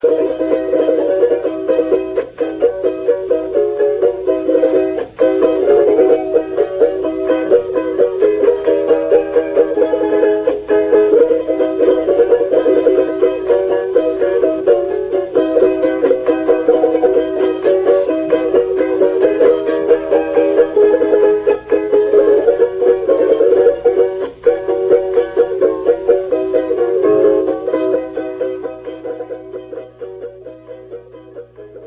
CHARANGO
Laúd, guitarra
Criollo, Indígena Quechua
Cordófono, compuesto, laúd.
Pequeña guitarra de madera de construcción artesanal con caja de resonancia de fondo cóncavo, uno de madera y otro de caparazón de armadillo, y diez cuerdas de nylon distribuidas en cinco órdenes dobles. Para ejecutarlo se emplea la técnica tradicional de la guitarra, la pulsación de las cuerdas se realiza con rasgueos y trémolos para el acompañamiento y punteo para las melodías.
Grabación: Villancico
Característica: Género musical propio de la Navidad
Procedencia, año: Villa Serrano, Prov. Belisario Boeto, Depto. Chuquisaca, Bolivia, 1973
charango.ra